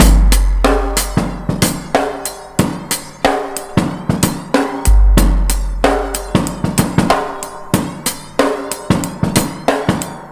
Index of /90_sSampleCDs/Best Service ProSamples vol.24 - Breakbeat [AKAI] 1CD/Partition B/ONE HAND 093